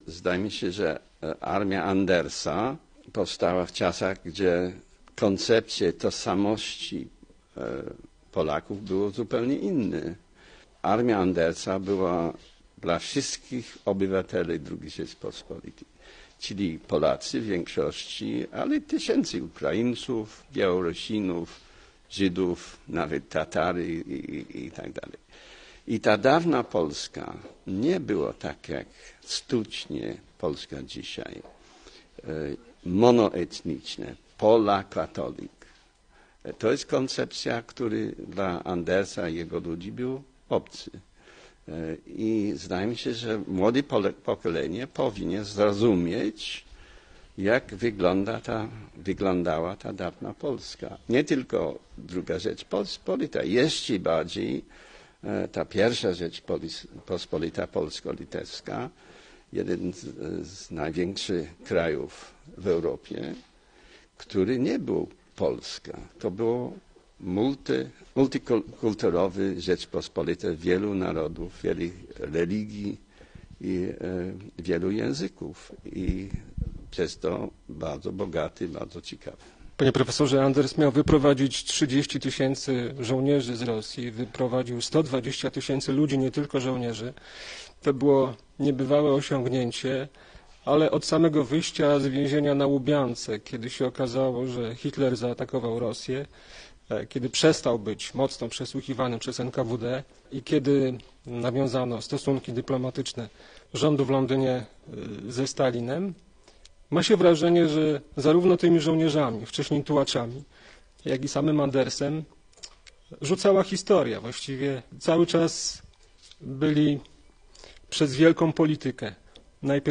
Rozmowa z brytyjsko - polskim historykiem, profesorem Uniwersytetu Londyńskiego, autorem prac dotyczących historii Europy, Polski i Wysp Brytyjskich, odbyła się w Domu Kultury w Kutnie.